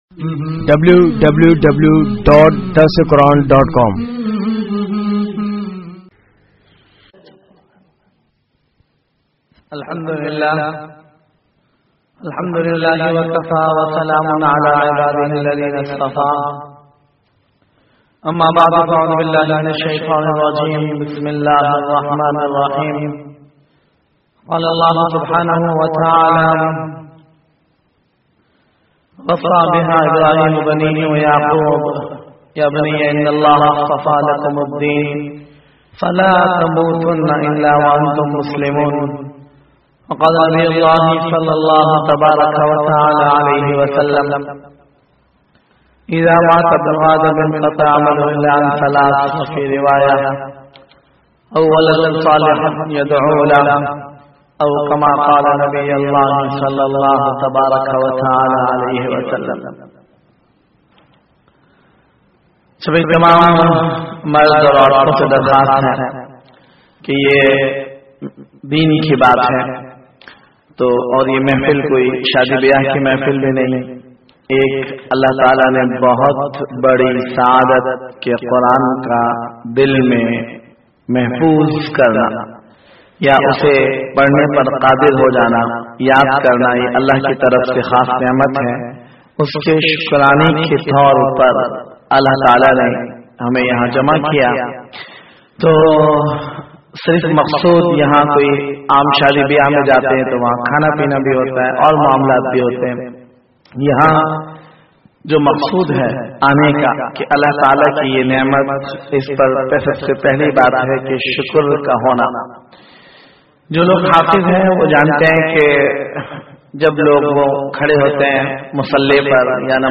Khush Naseeb Aur Bad Naseeb Maa Baap PRG GIRLS JALSA